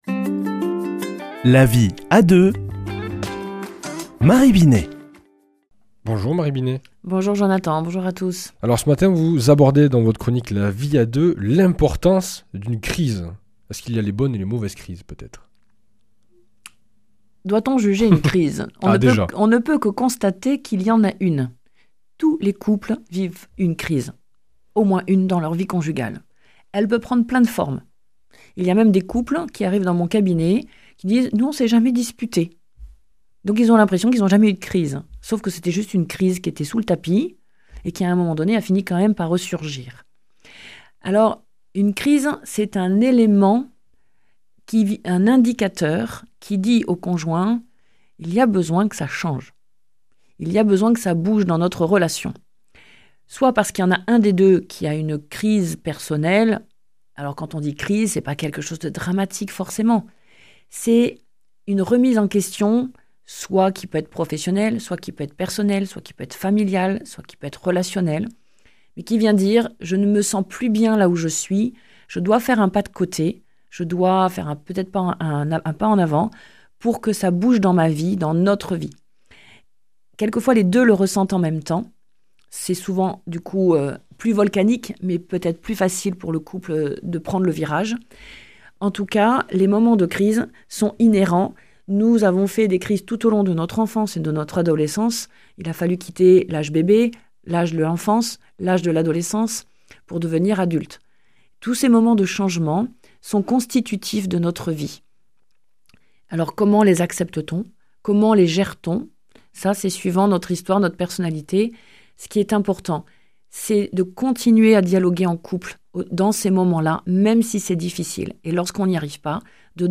mardi 3 décembre 2024 Chronique La vie à deux Durée 4 min